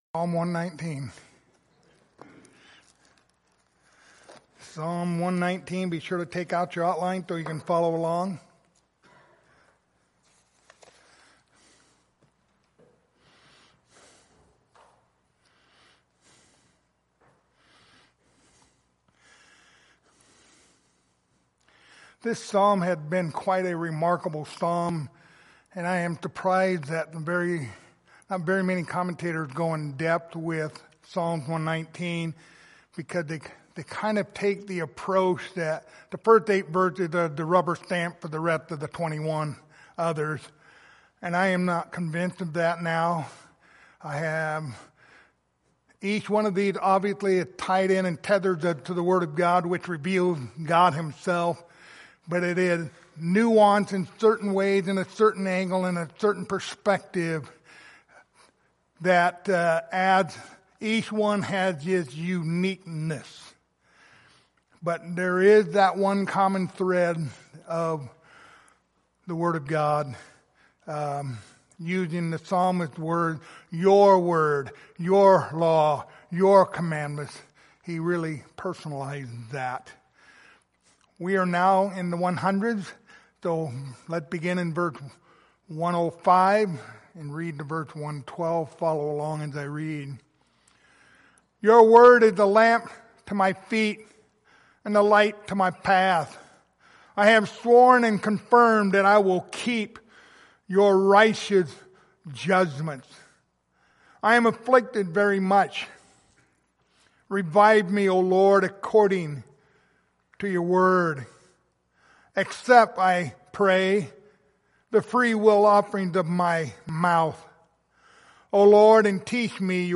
Passage: Psalm 119:105-112 Service Type: Sunday Morning